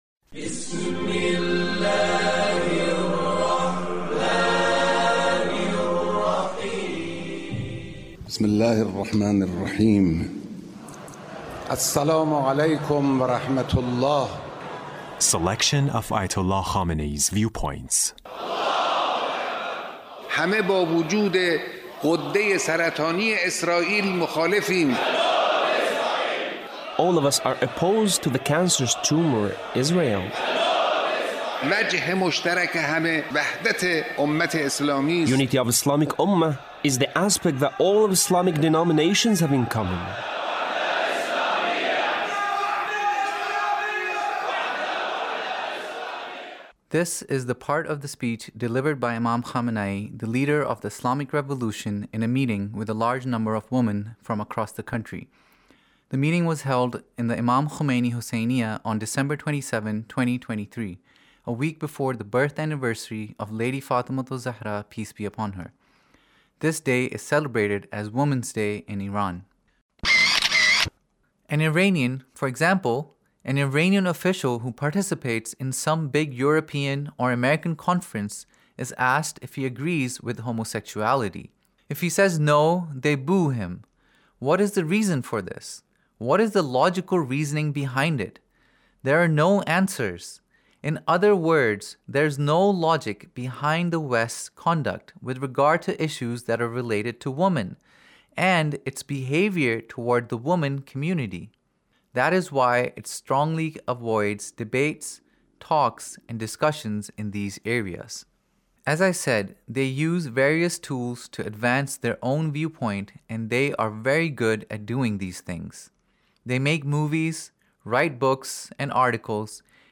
Leader's Speech about , in a meeting with Ladies